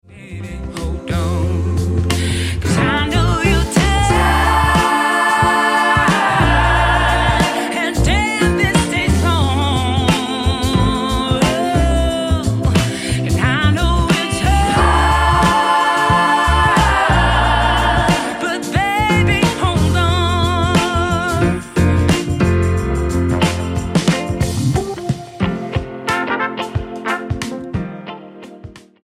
STYLE: R&B
breezy and optimistic soul